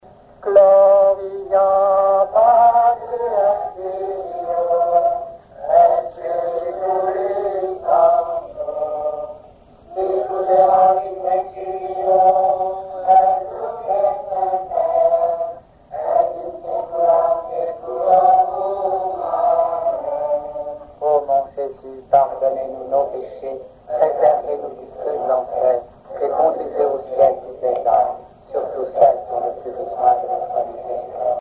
prière, cantique
Genre strophique
Pièce musicale inédite